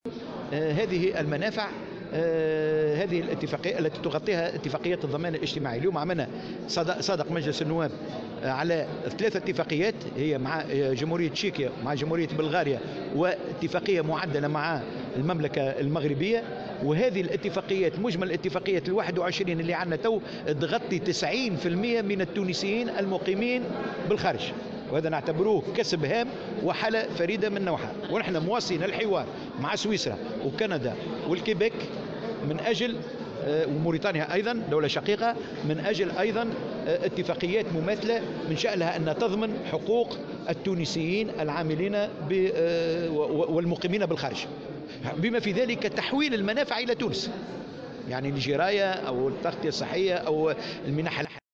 وقال في تصريحات صحفية ان هذه الاتفاقيات تشمل كل من تشيكيا وبلغاريا والمغرب.